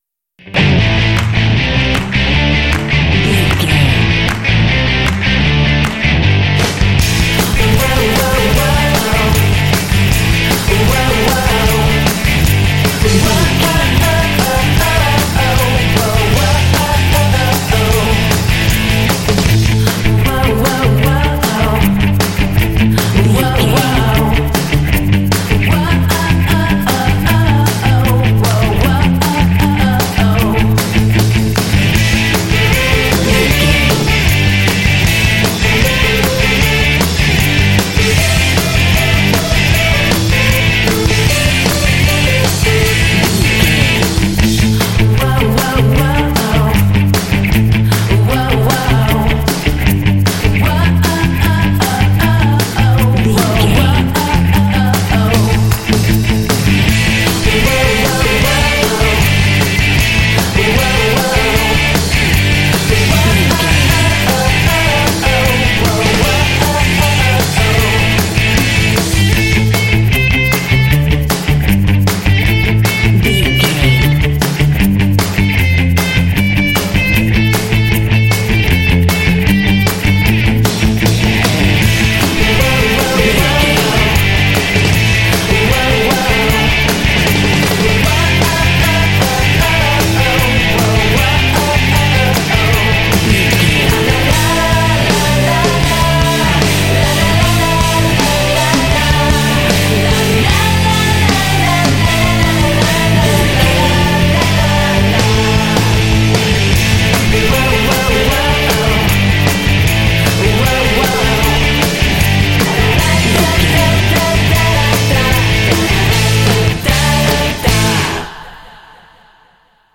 This Pop track is full of energy and drive.
Ionian/Major
driving
energetic
electric guitar
bass guitar
drums
electric piano
vocals
pop